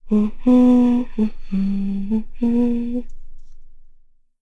Laudia-Vox_Hum.wav